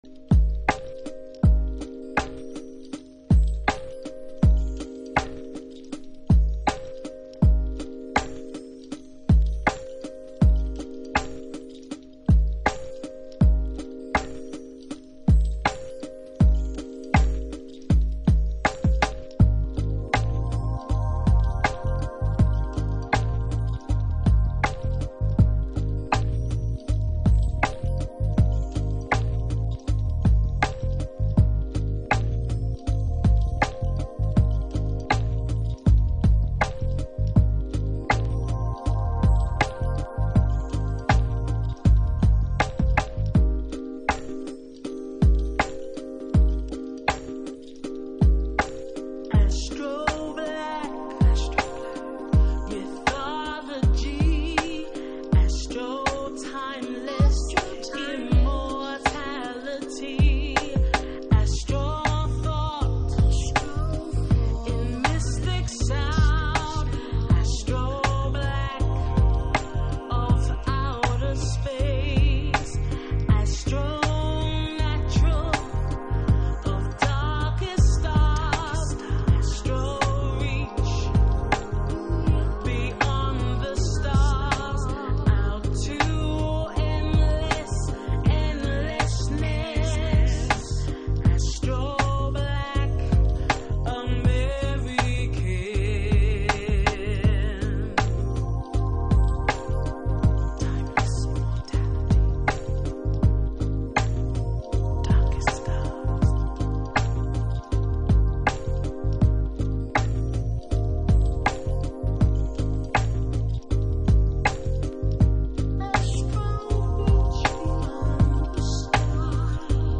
TOP > Future Jazz / Broken beats > VARIOUS